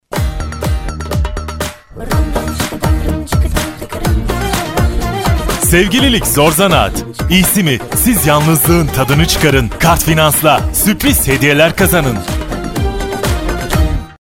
Soy un actor de doblaje turco profesional.
Cálido
Conversacional
Seguro